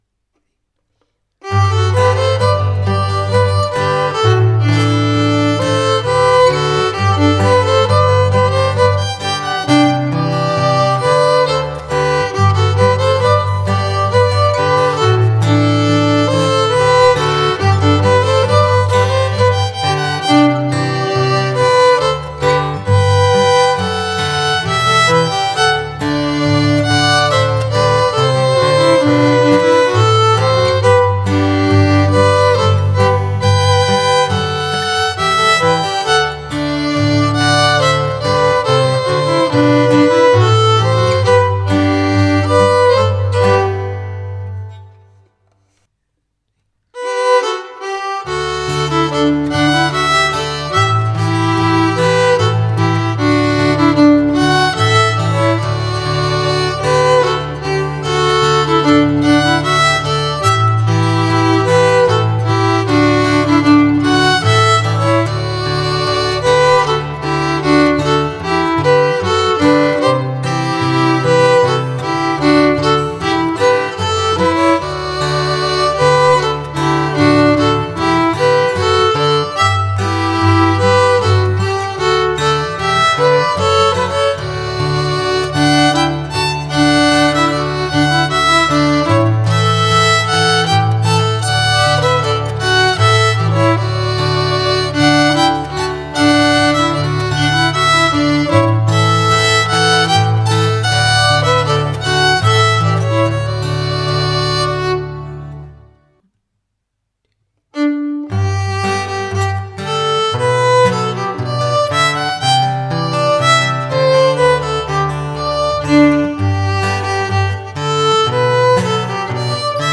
Tune, harmony and guitar (Rusty Gulley, Reedhouse Rant, The Trincentannial)
Morpeth-26-tune-harmony-and-guitar.mp3